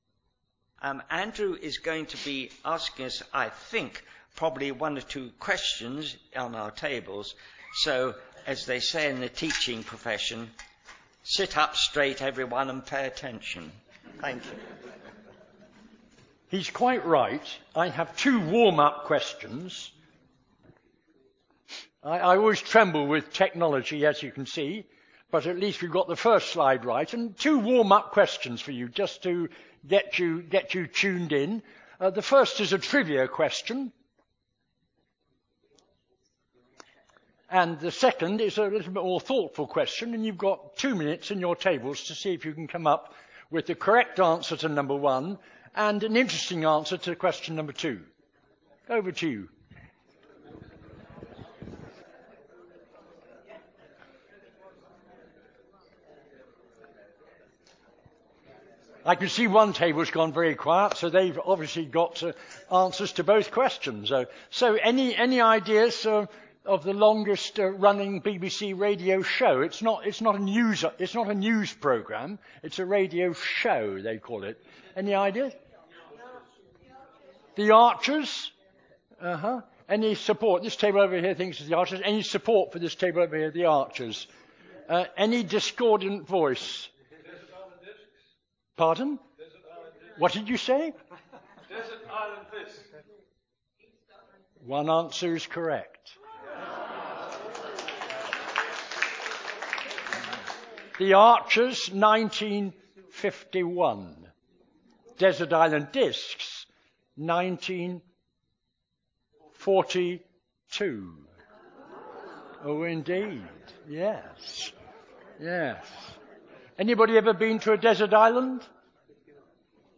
Media for Senior Fellowship on Tue 11th Feb 2025 10:30 Speaker
In the beginning… God Sermon Search media library...